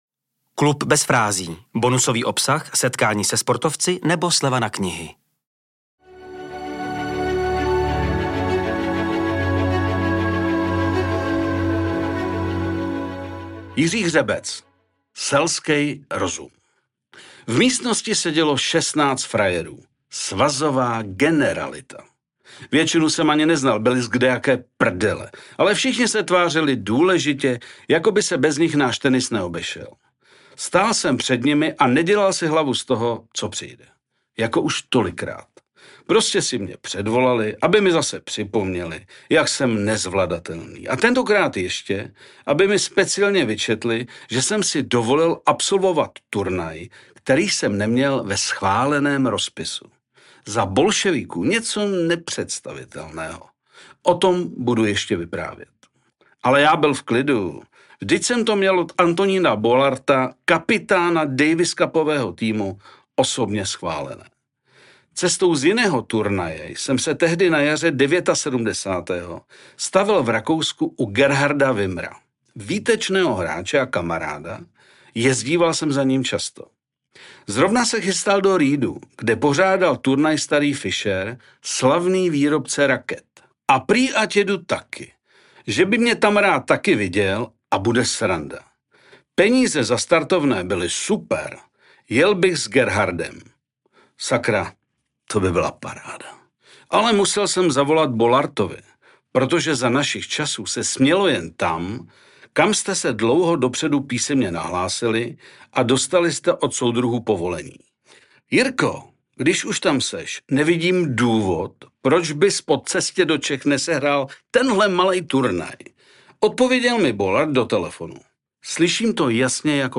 Poslechněte si příběh Bez frází, který je o nenávisti k Bolševikům ale především lásce k tenisu. Ke hře, kterou Jiří Hřebec ovládl, aby ji následně učil další generace. Načetl vám ho vynikající Miloš Pokorný .